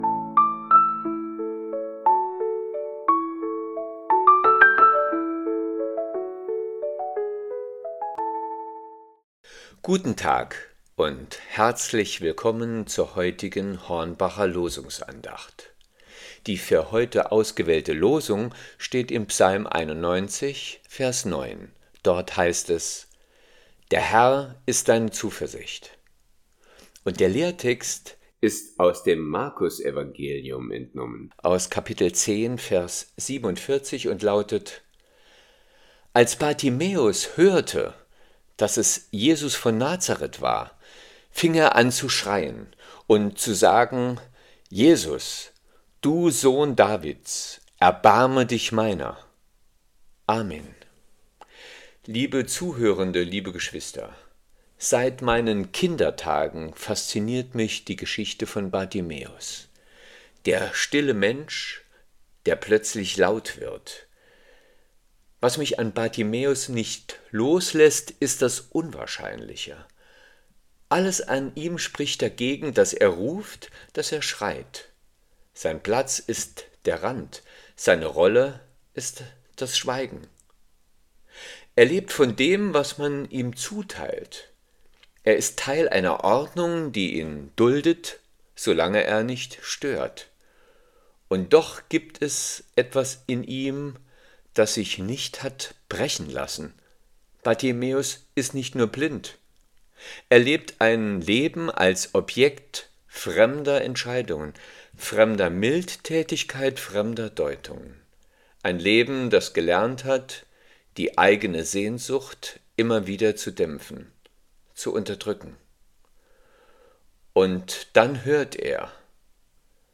Losungsandacht für Samstag, 17.01.2026